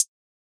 Closed Hats
{HH} Regularo.wav